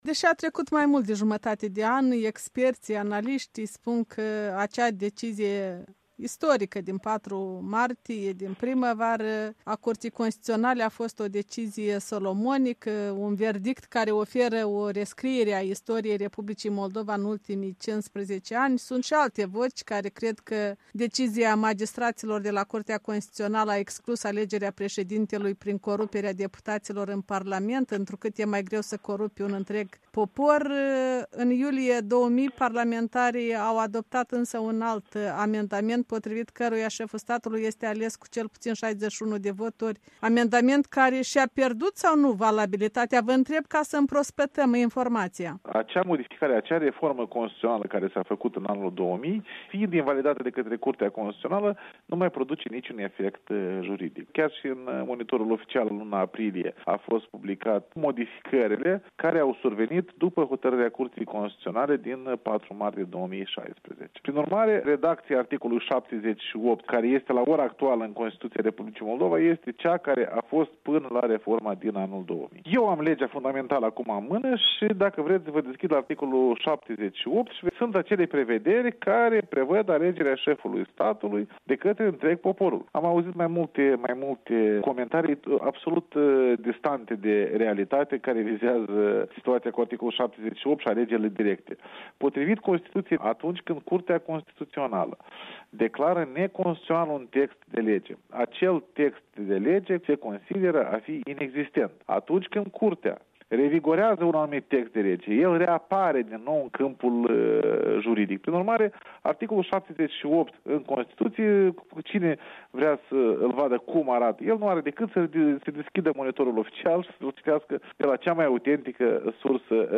Alexandru Tănase, președintele CC, răspunde întrebărilor Europei Libere